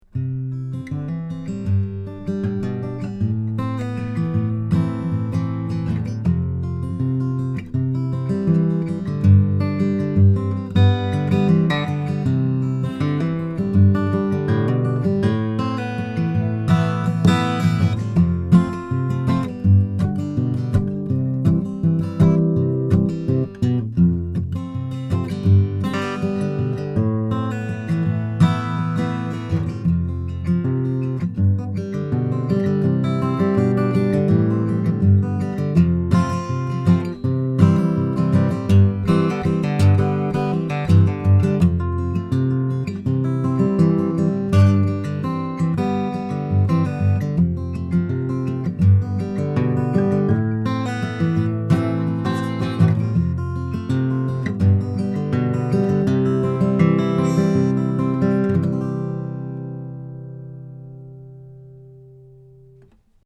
Here are some quick, 1-take MP3 sound files showing how each pattern sounds through a Presonus ADL 600 preamp into a Rosetta 200 A/D converter.
Santa Cruz OM/PW Guitar
FIG. 8 NULL SIDE